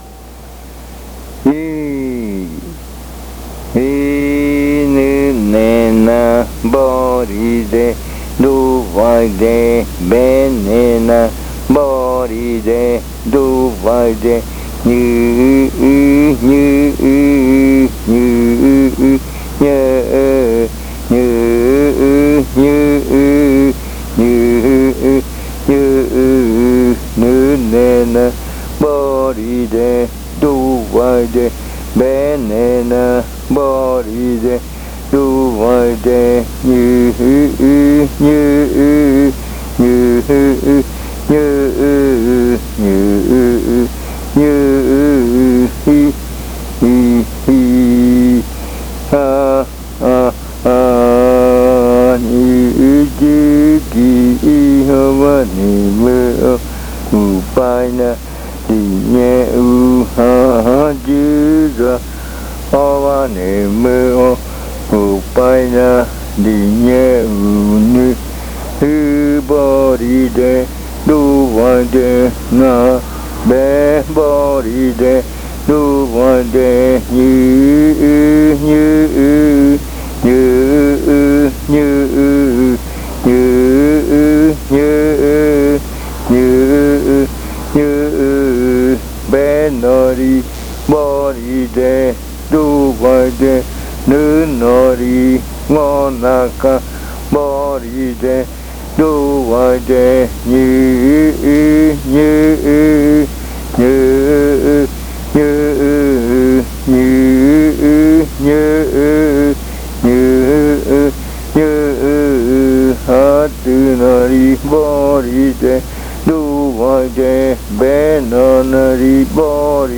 Adivinanza. Dice: “¿De dónde relampaguea?, de este lugar de aquí rio abajo, de allá, etc.”
Este canto hace parte de la colección de cantos del ritual Yuakɨ Murui-Muina (ritual de frutas) del pueblo Murui
Riddle chant.
This chant is part of the collection of chants from the Yuakɨ Murui-Muina (fruit ritual) of the Murui people